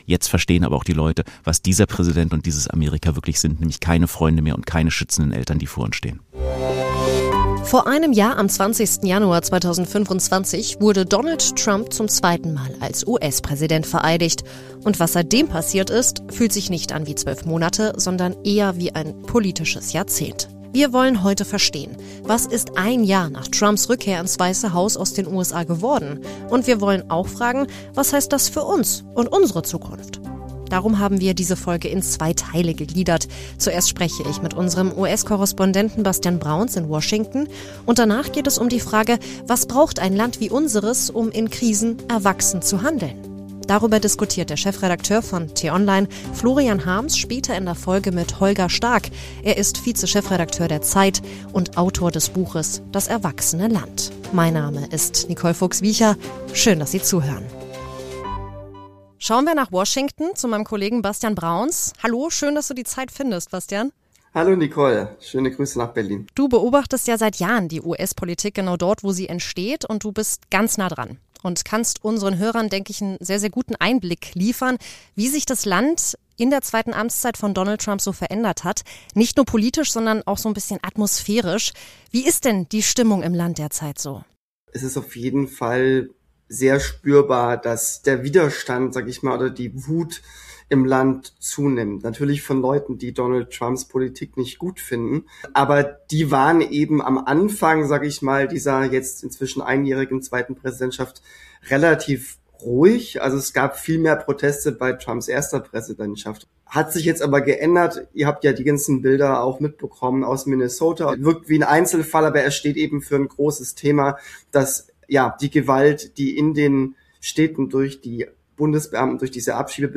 Tagesanbruch – die Diskussion Ein Jahr nach Donald Trumps Rückkehr ins Weiße Haus wirkt die politische Lage in den USA wie nach einem Jahrzehnt des Umbruchs.